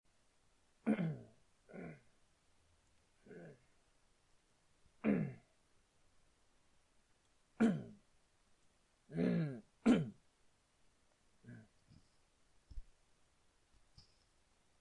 Tag: 回声 气泡 打嗝 打嗝回声 恶心 肚子饿了 blurp 内脏 食物 饥饿 肚子